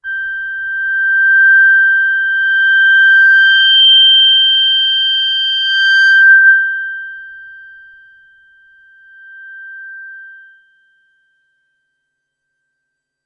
标签： FSharp7 MIDI音符-103 Korg的-Z1 合成器 单票据 多重采样
声道立体声